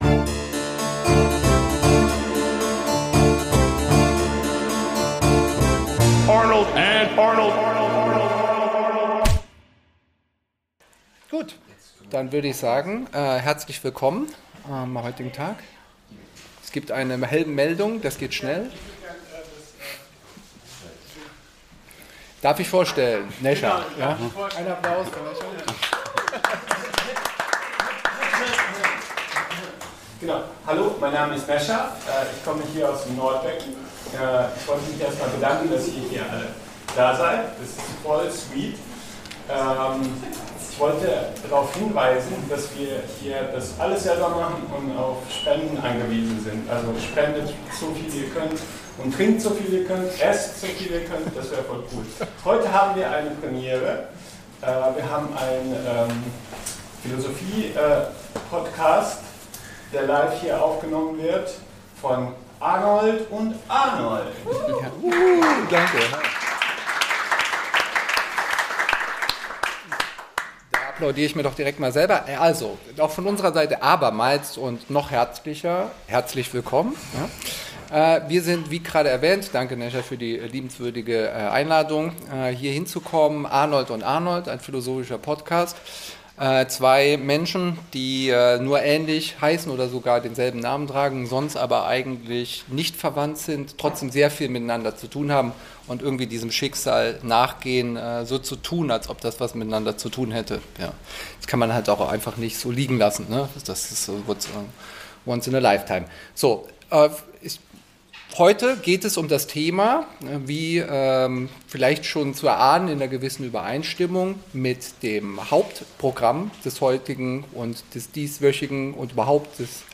Live aus dem Nordbecken Karlsruhe